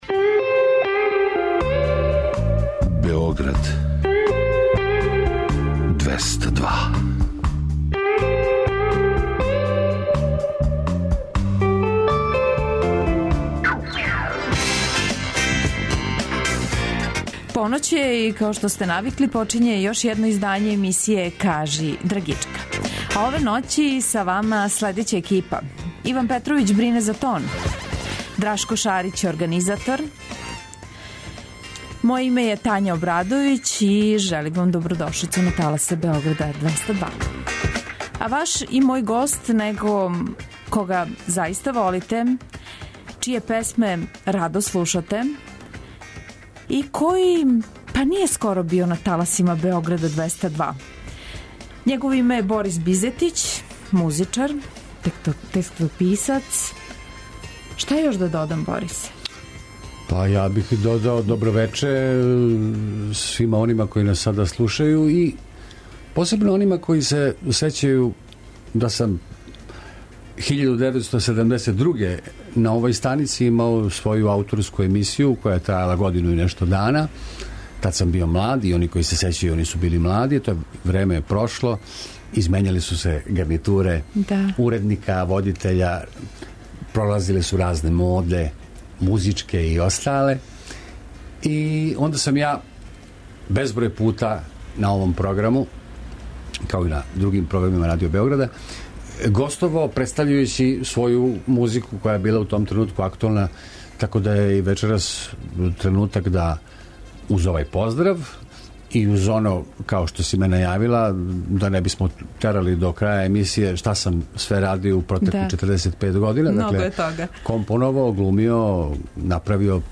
Гост вечерашње емисије Кажи драгичка (00.00) је композитор Борис Бизетић.